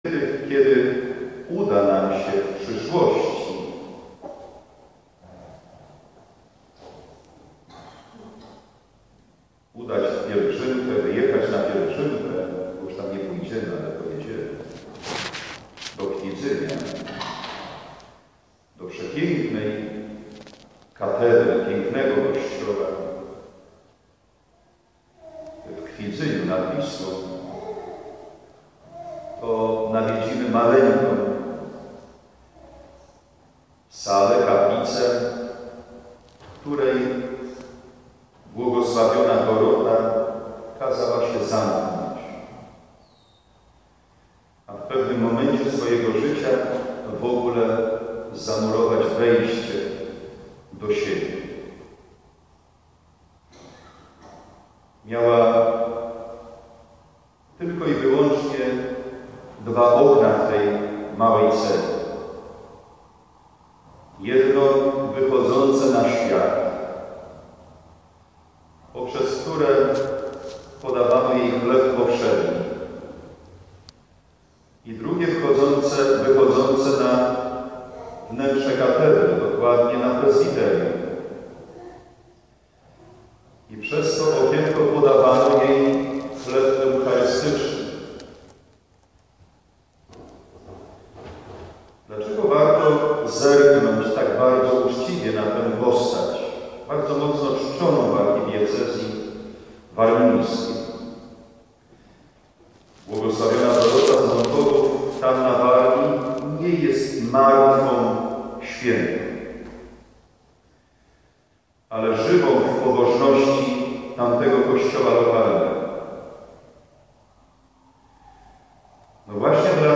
25 czerwca 2015 r. – Msza św. – homilia